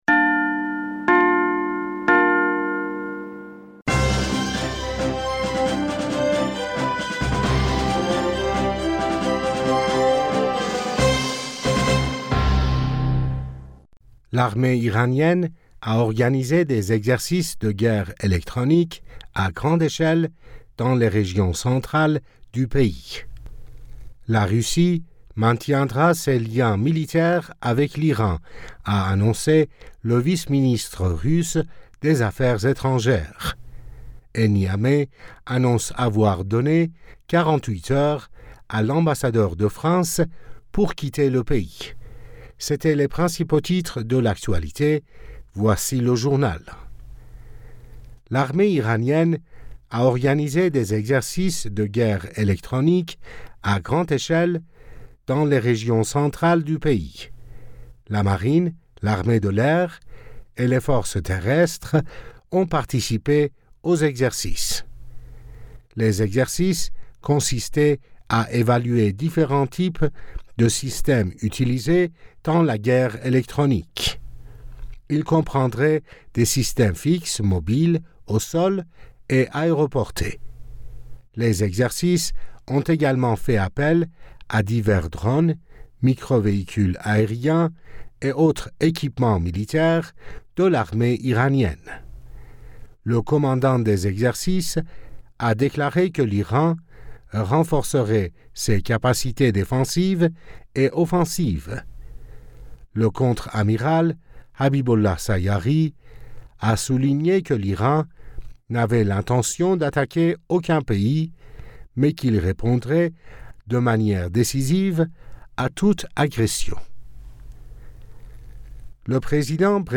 Bulletin d'information du 26 Aout 2023